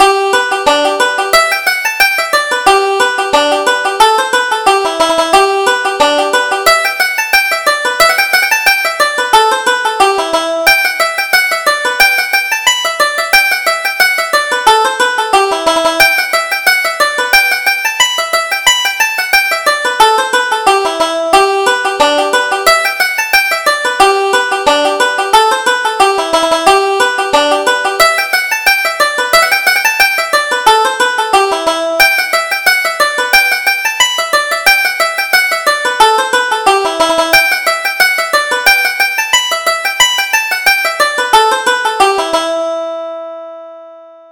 Reel: After the Sun Goes Down